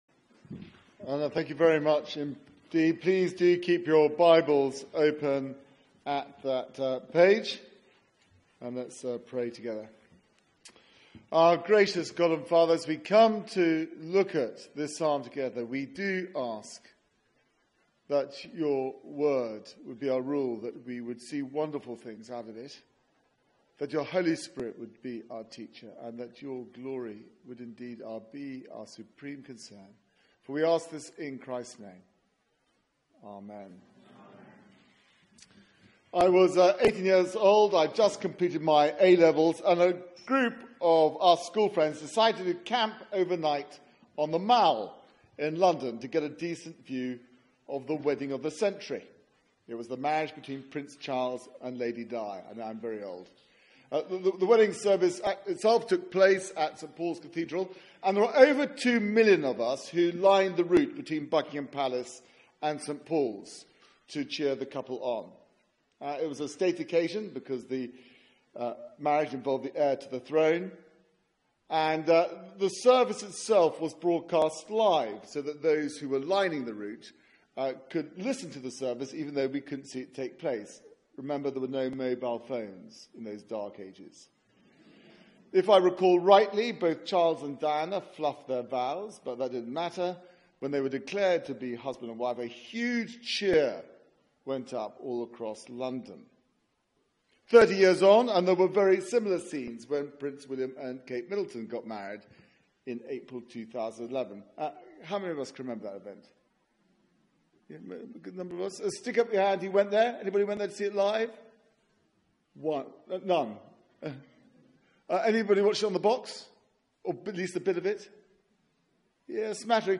Media for 6:30pm Service on Sun 06th Aug 2017 18:30 Speaker
psalm 45 Series: Summer Psalms Theme: A royal wedding Sermon Search the media library There are recordings here going back several years.